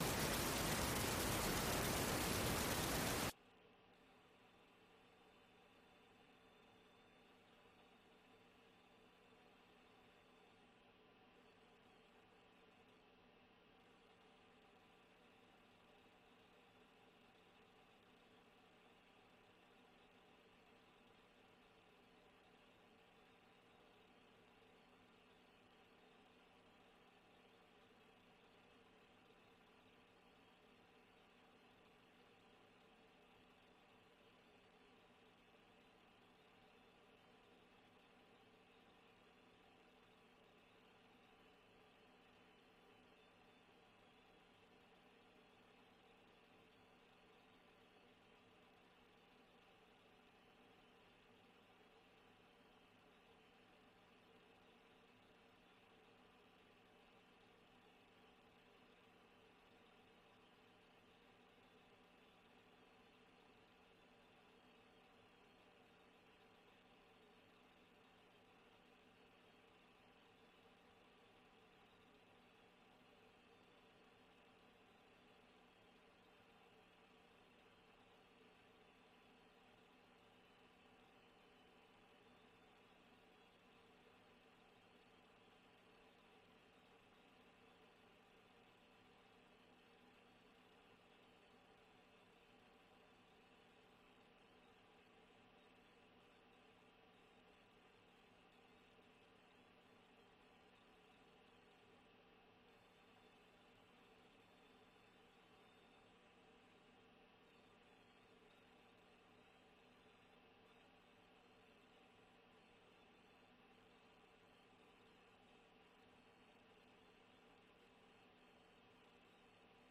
VOA 한국어 '출발 뉴스 쇼', 2022년 9월 23일 방송입니다. 조 바이든 대통령과 윤석열 한국 대통령이 유엔총회가 열리고 있는 뉴욕에서 만나 북한 정권의 위협 대응에 협력을 재확인했습니다. 한일 정상도 뉴욕에서 대북 협력을 약속하고, 고위급 외교 채널을 통해 양국 관계 개선 노력을 가속화하기로 합의했습니다. 바이든 대통령은 유엔총회 연설에서 북한의 지속적인 유엔 제재 위반 문제를 지적했습니다.